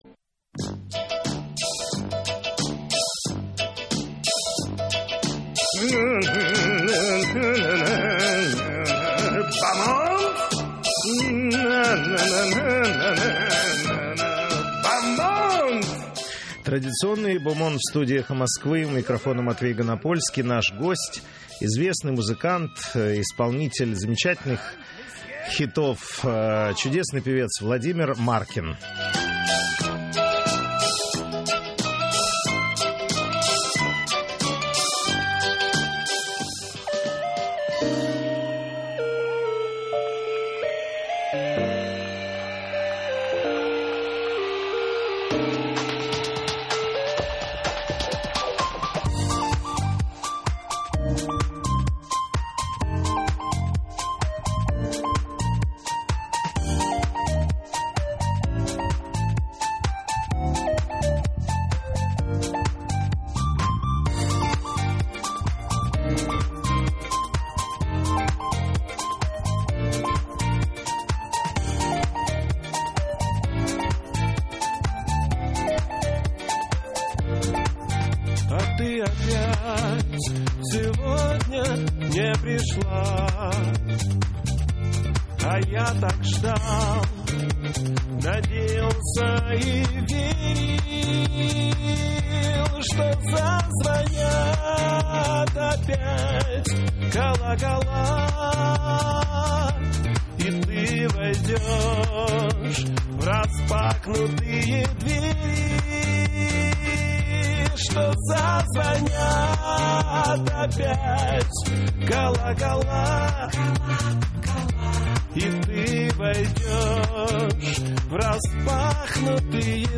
В эфире традиционный «Бомонд». У нас в гостях музыкант, исполнитель замечательных хитов, чудесный певец Владимир Маркин.